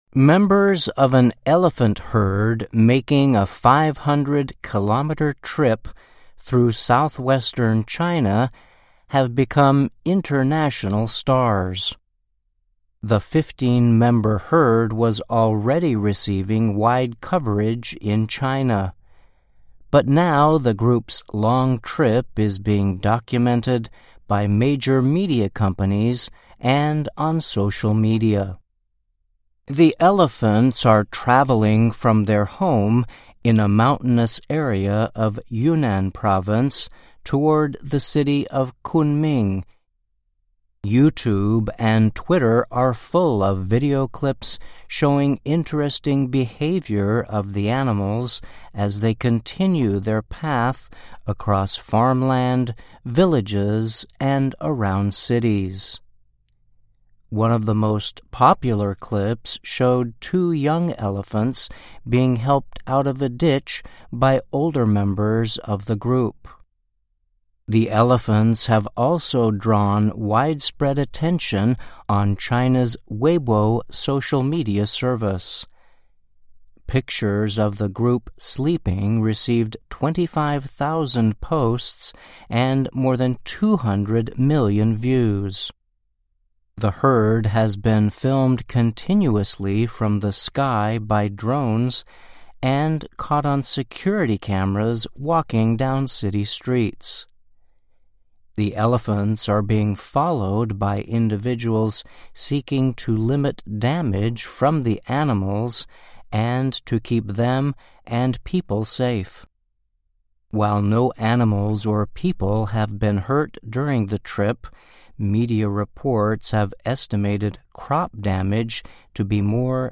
VOA慢速英语(翻译+字幕+讲解):中国云南北迁象群引发全球关注MP3音频下载,在中国西南部徒步500公里的象群已经成为了国际明星。